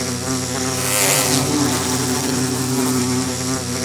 Minecraft Version Minecraft Version latest Latest Release | Latest Snapshot latest / assets / minecraft / sounds / mob / bee / aggressive3.ogg Compare With Compare With Latest Release | Latest Snapshot
aggressive3.ogg